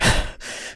breath4.ogg